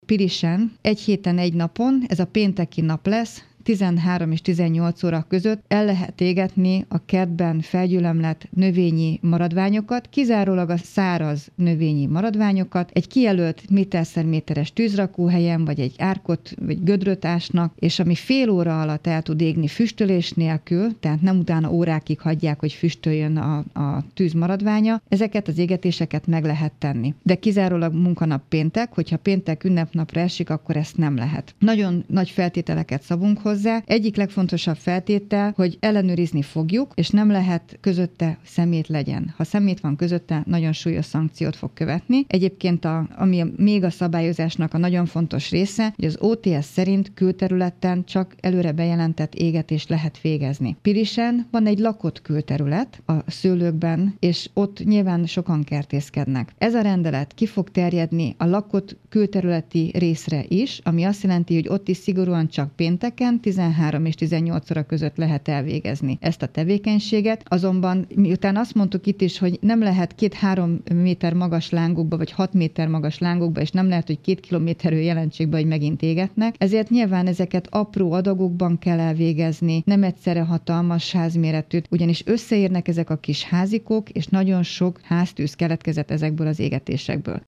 Hajnal Csilla polgármester ismertette a szabályokat.